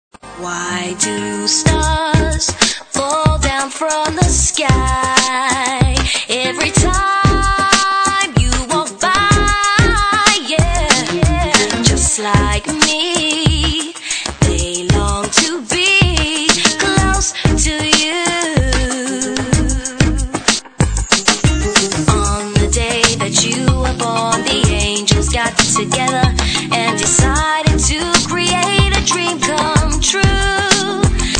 Soul/R&B/Jazz